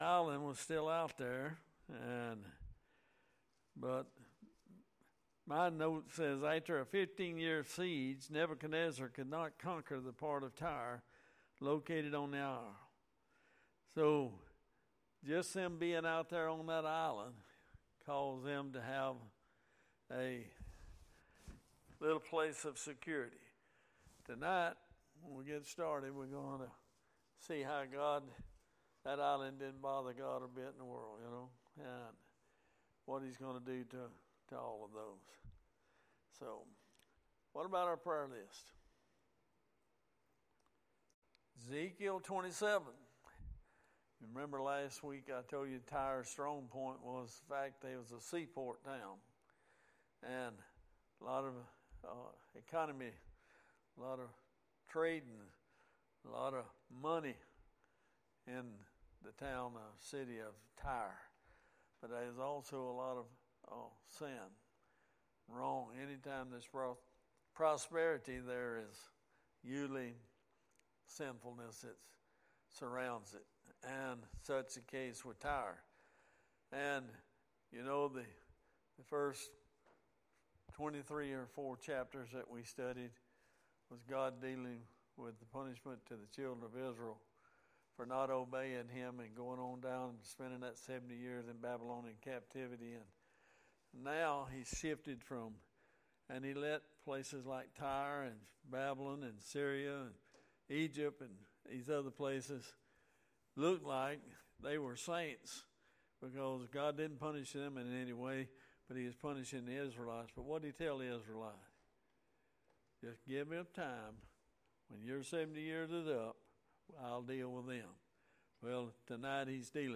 Sermons | Bexley Baptist Church
Bible Study